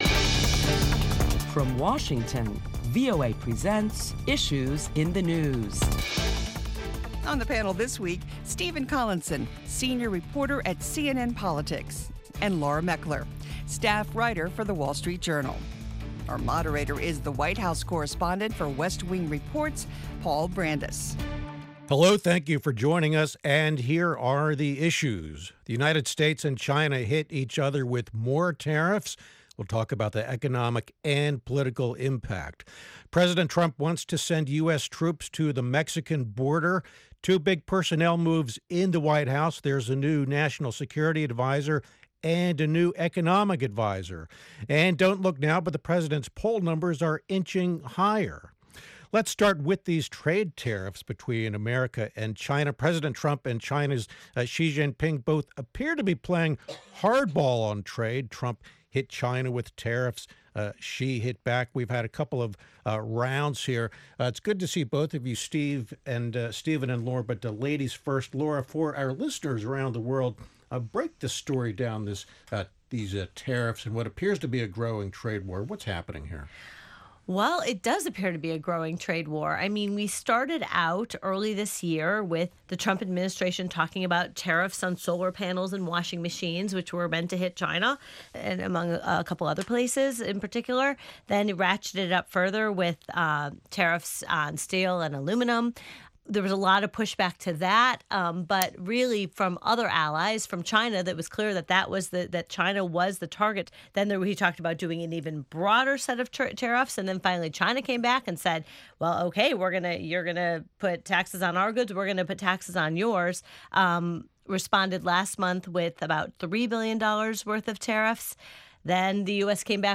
This week on Issues in the News, top Washington journalists talk about the week's headlines including President Trump’s modest bump in the polls amid proposed trade tariffs, toughness on immigration and more cabinet changes.